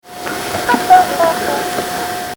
Then I heard a small laugh that I hadn't noticed before.
Just your typical mocking laugh in an old cemetery.